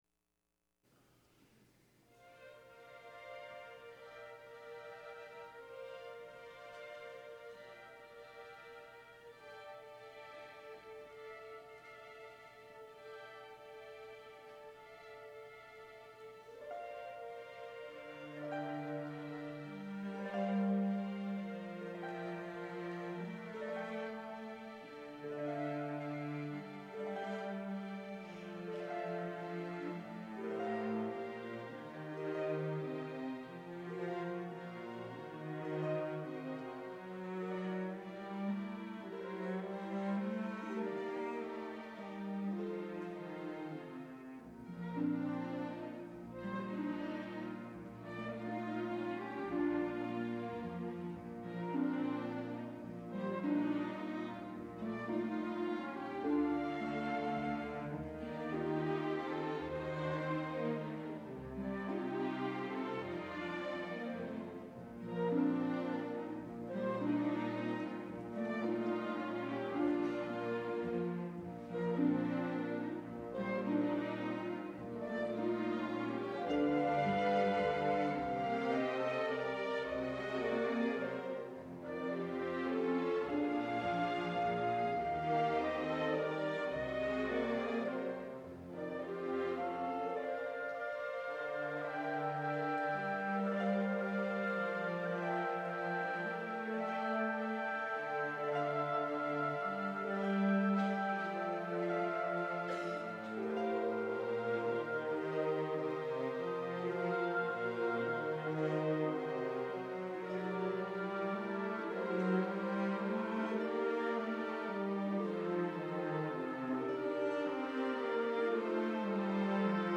Ensemble: Chamber Orchestra